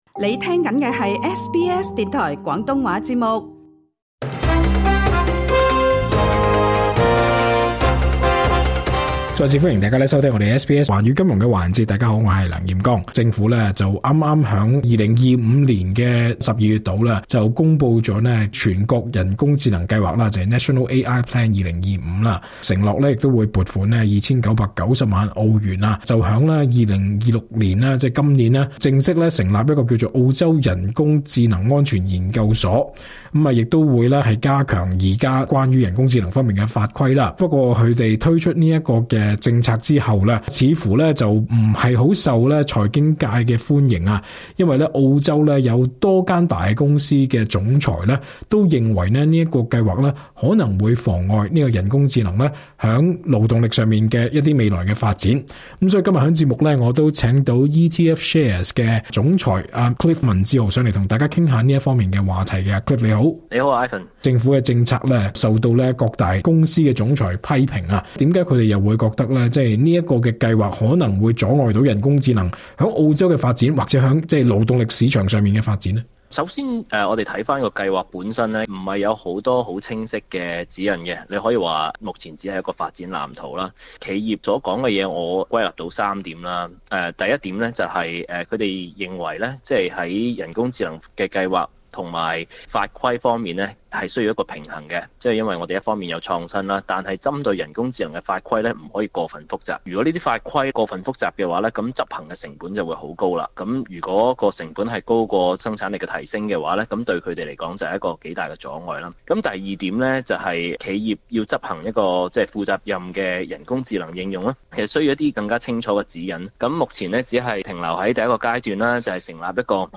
完整訪問： LISTEN TO 【國家人工智能計劃】大企業老闆憂阻礙本地AI發展 SBS Chinese 11:16 yue 《澳洲人報》日前進行的調查，多名澳洲頂尖企業的領袖對新規管表示擔憂。